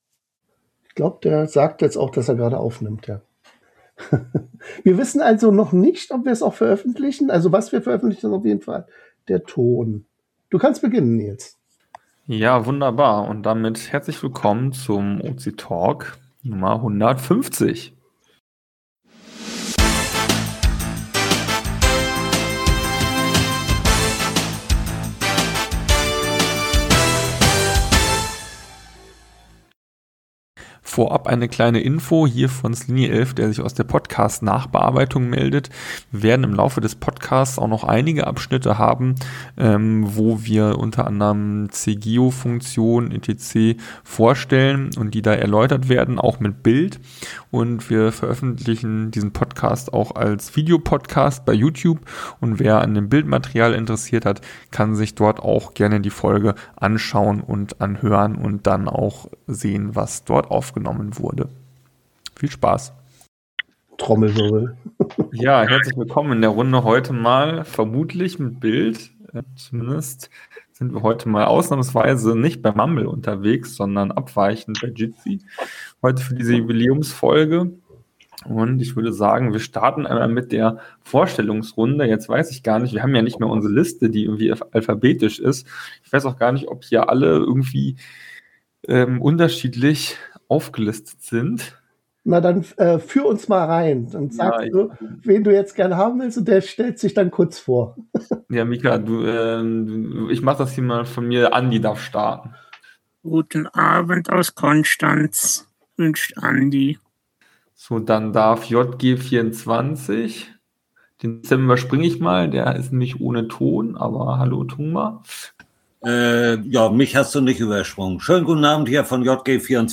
OC Talk 150 - Interview mit c:geo und Updates bei Opencaching ~ OC Talk (MP3 Audio) Podcast